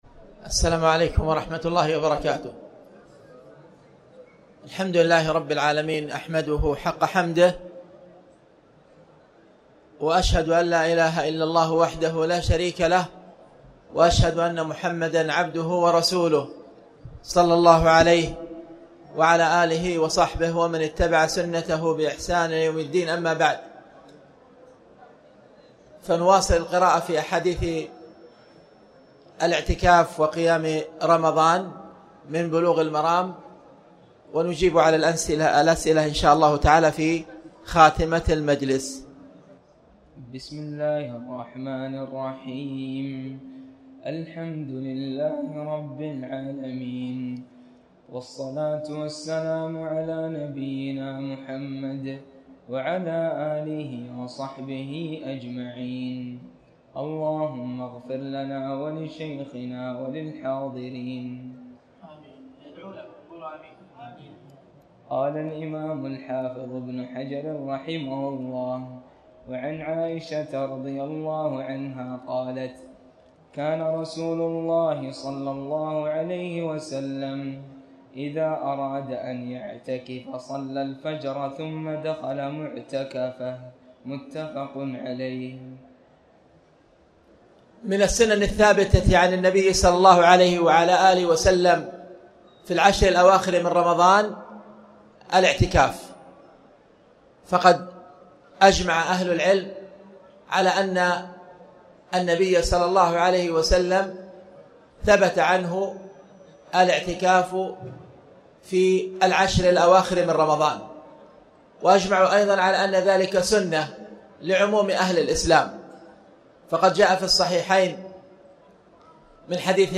تاريخ النشر ١٨ رمضان ١٤٣٩ هـ المكان: المسجد الحرام الشيخ